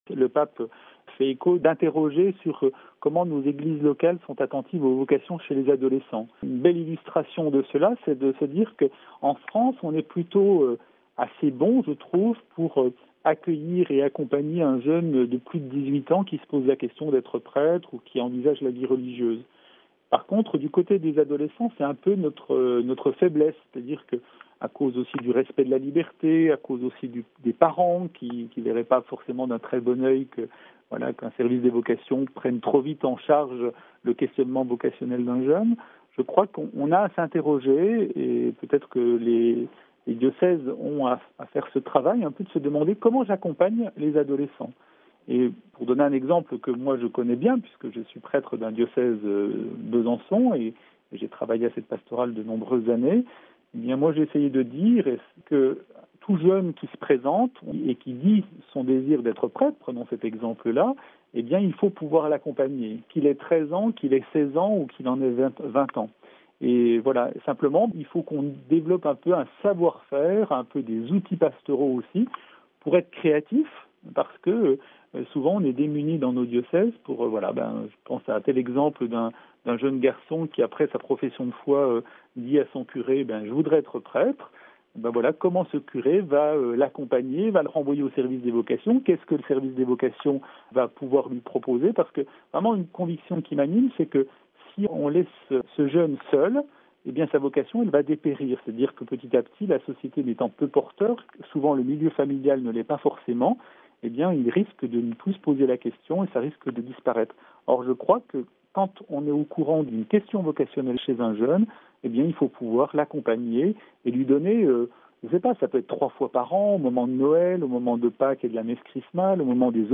interrogé